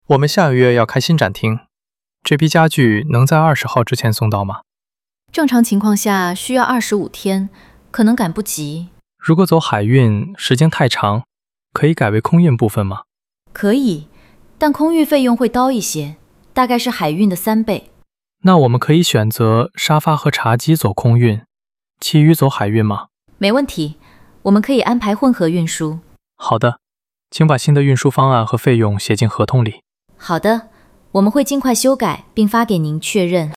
Hội thoại 3: Thương lượng về vận chuyển & thời gian giao hàng